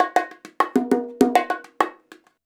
100BONG13.wav